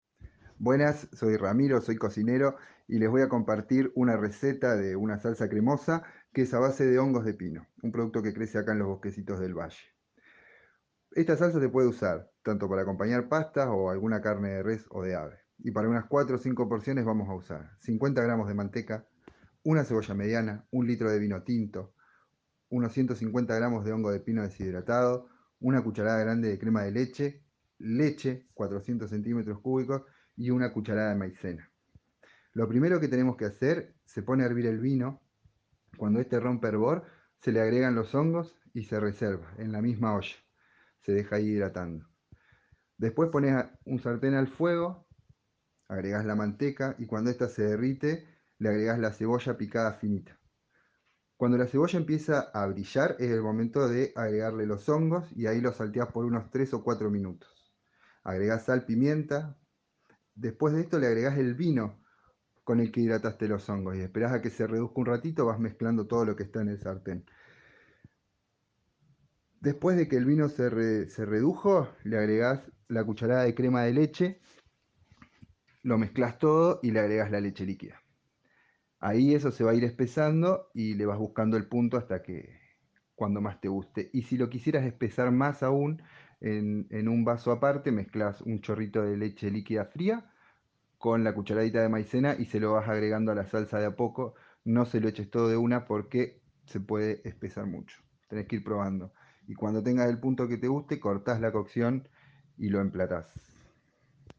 Audio receta de salsa cremosa de hongo de pino